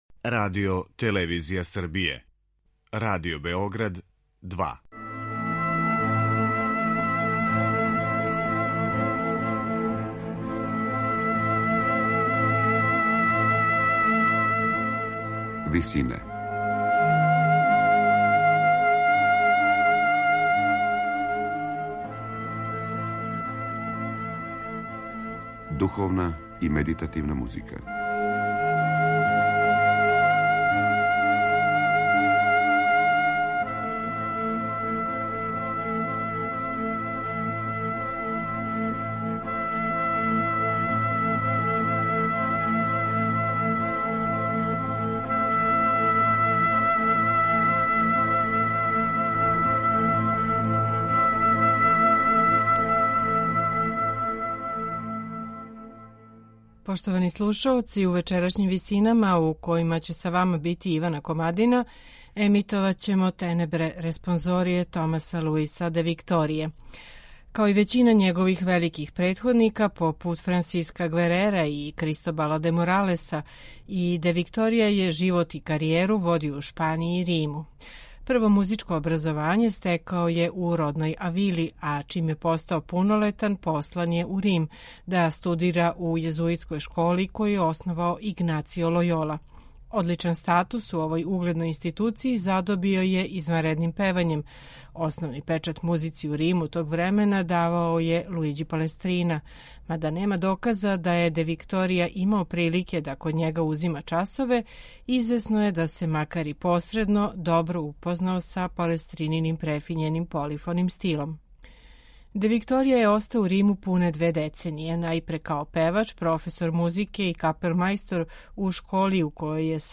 медитативне и духовне композиције
У вечерашњим Висинама слушаћемо Де Викторијине „Tenebrae" за Велики четвртак и Велики петак у интерпретацији камерног хора „Lumen valo".